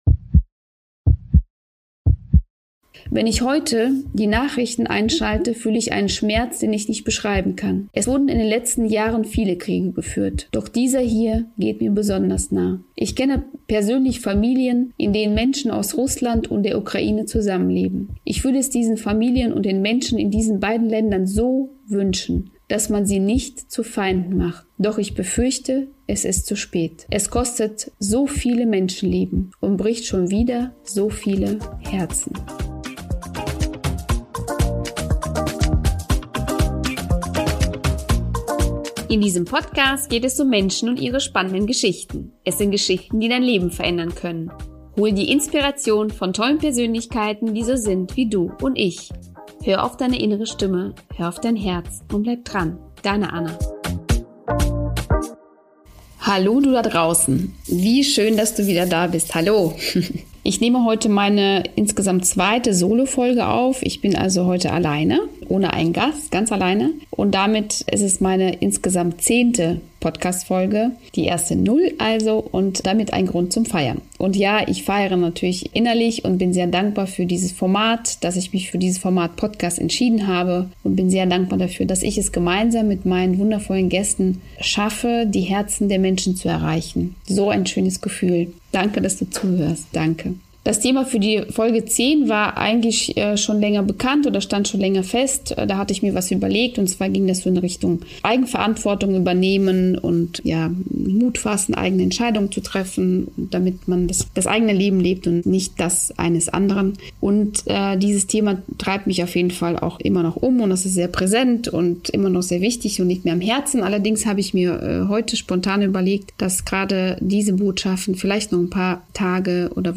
Es gibt eine Solofolge, ich möchte sie unseren inneren Kämpfen widmen. Diese Folge ist sehr persönlich und entsprechend dem Thema nicht so fröhlich wie sonst.